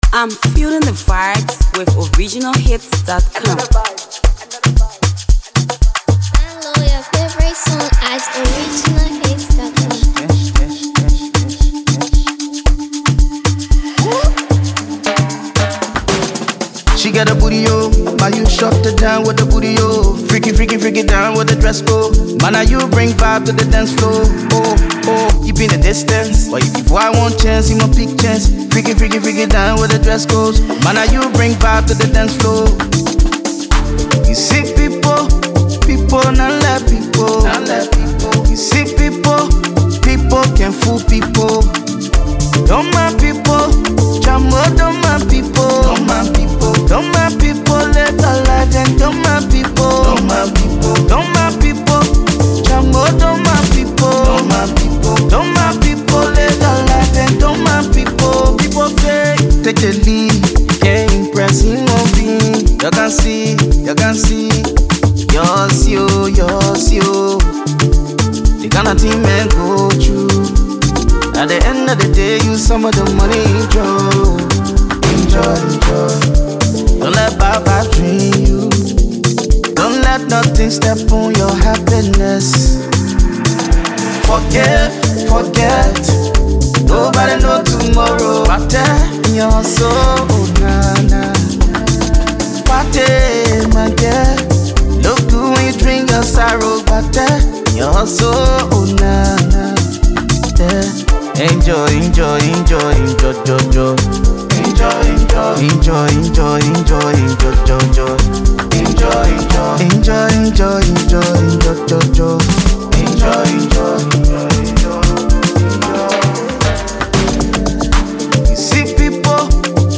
smooth vocals
pulsating rhythm